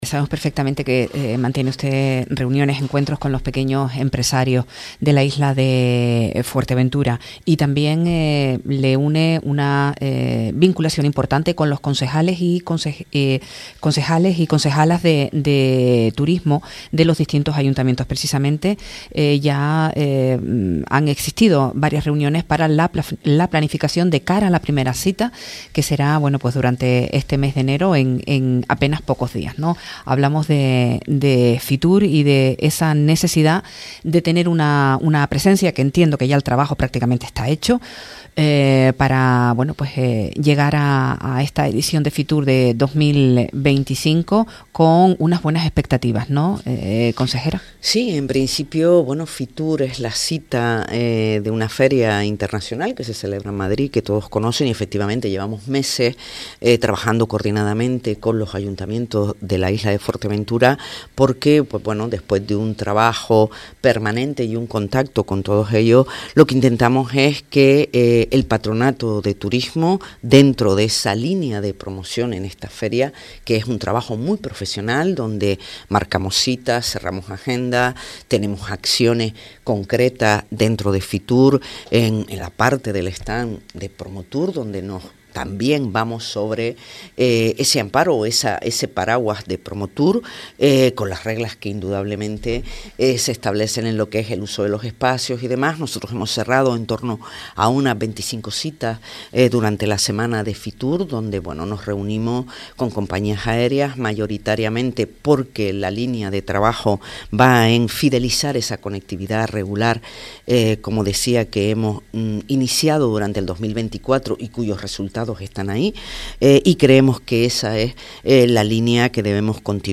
Marlene Figueroa, consejera de Turismo del Cabildo de Fuerteventura
Entrevistas